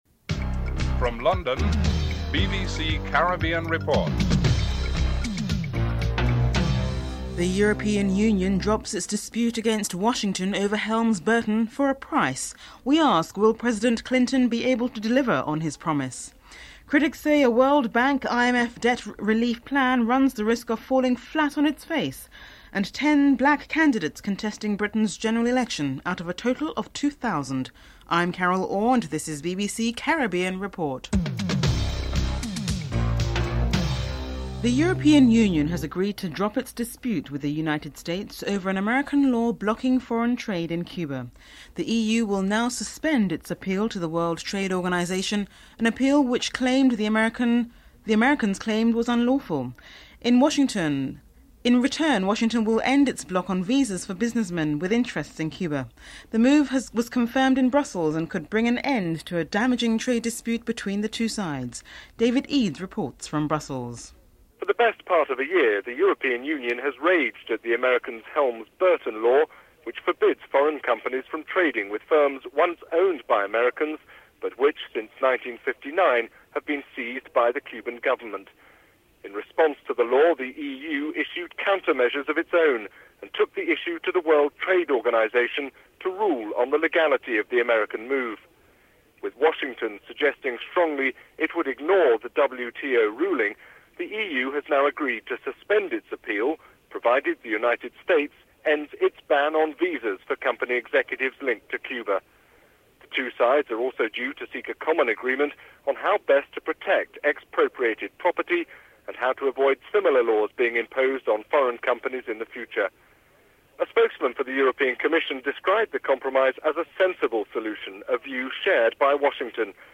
Prime Minister of Grenada, Dr Keith Mitchell is interviewed (05:36-07:04)
Governor Frank Savage is interviewed (09:51-12:52)